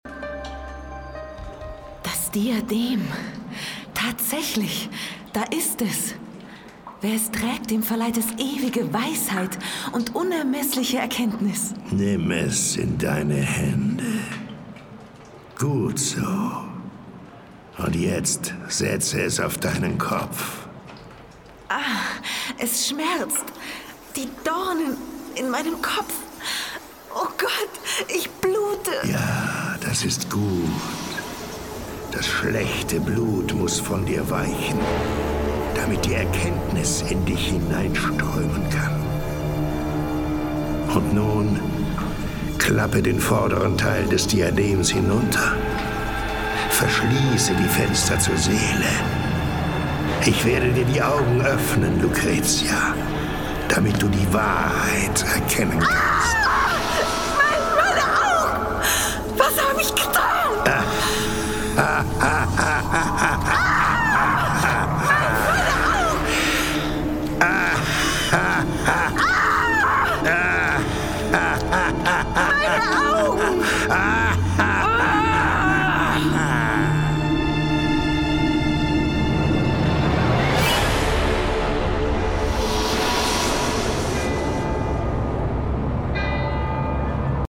Hörspiele | Synchron